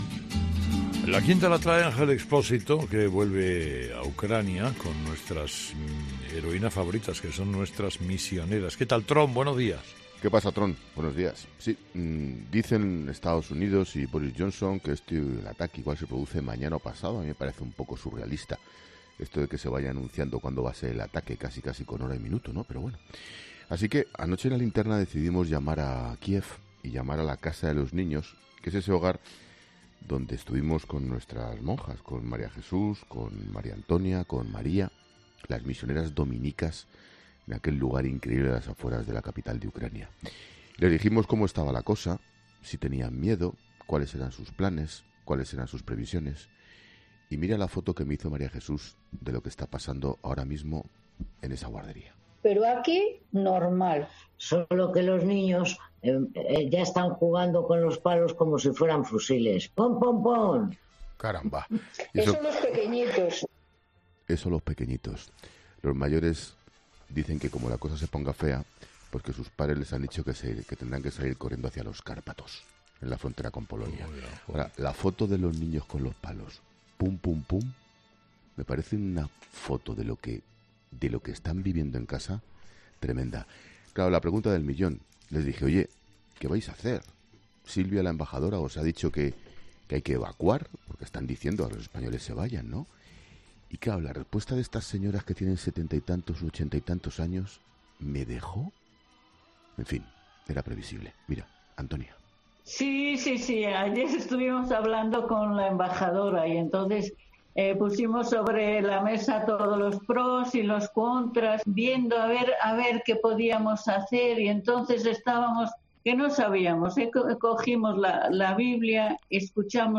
Ángel Expósito hace su paseíllo en 'Herrera en COPE'.